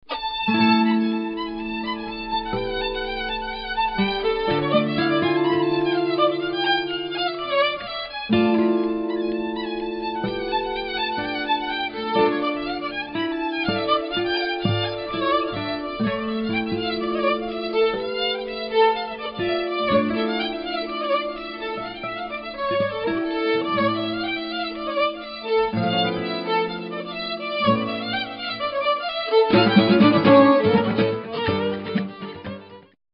Fiddle, Banjo, Guitar, Percussion, Bass.